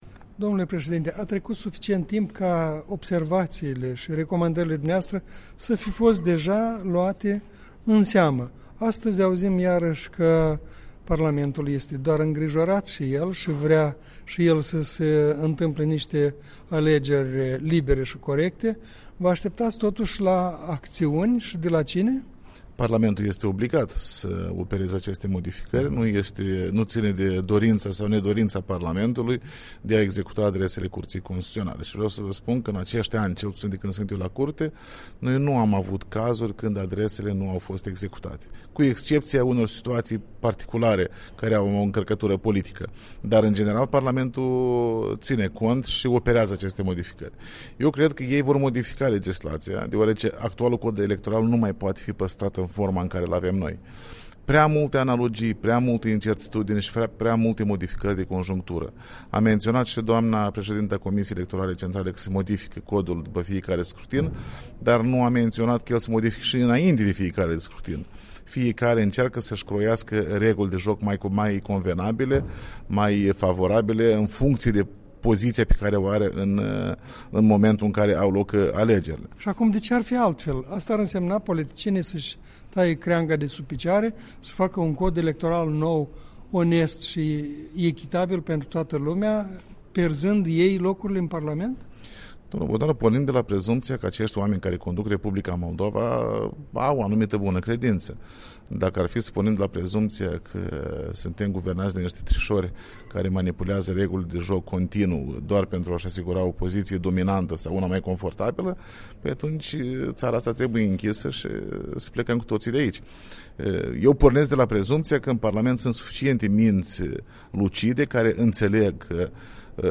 Interviurile Europei Libere: de vorbă cu Alexandru Tănase președintele CC